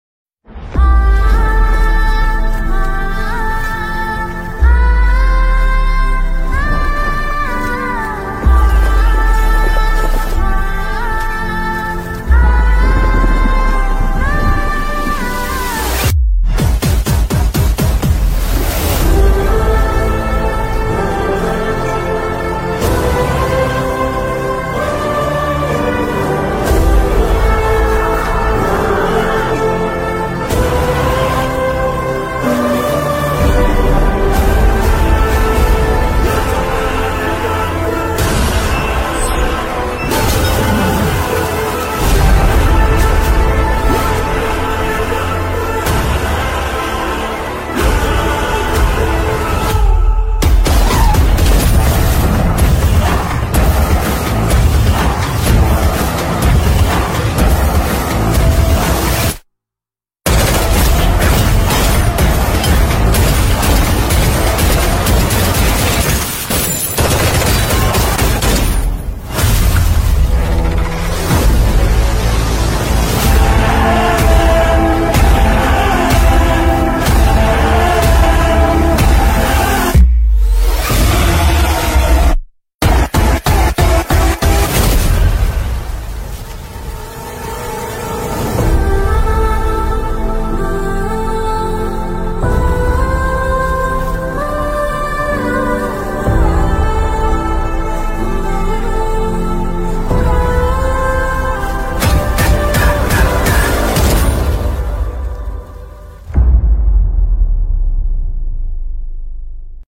Categories BGM Ringtones